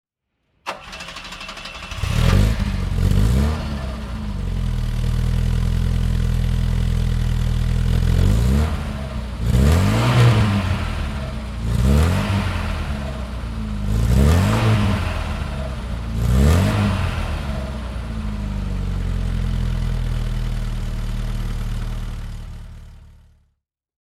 Opel Kadett 1.2 SR Coupé (1978) - Starten und Leerlauf
opel_kadett_c_coupe_1978.mp3